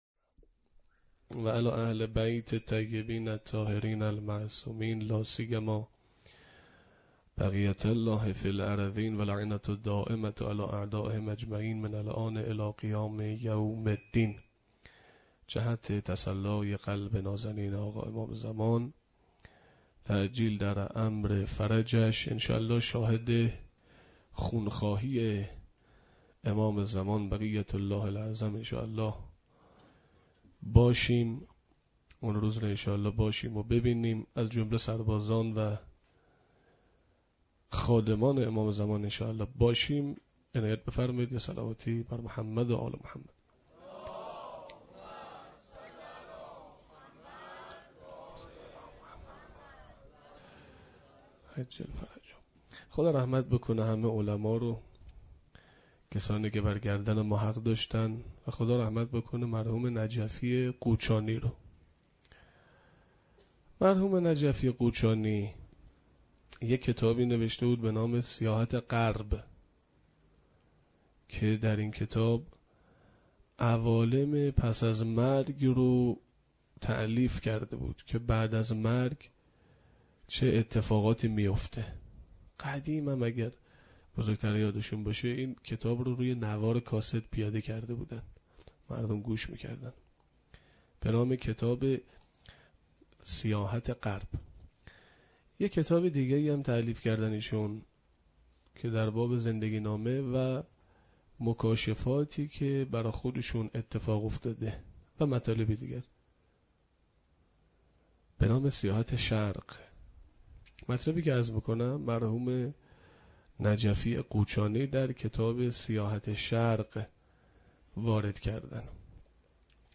سخنرانی.mp3